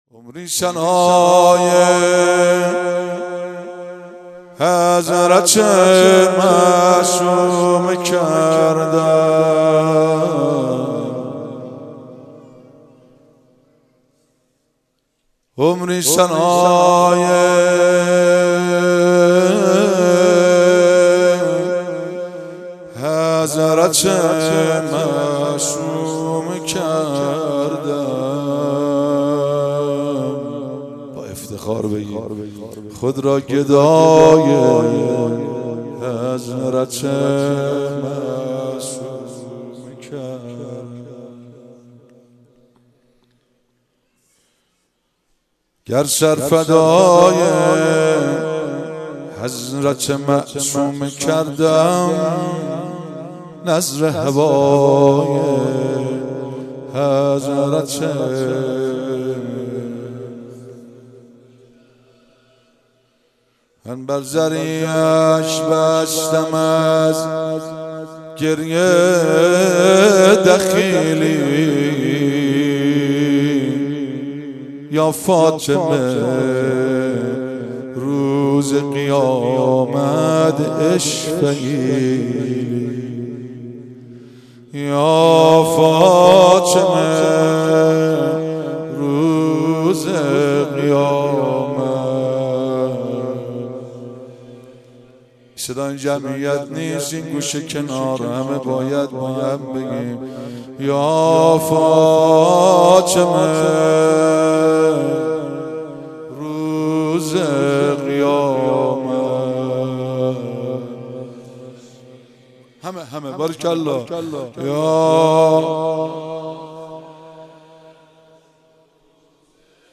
6 دی 96 - هیئت فاطمیون - روضه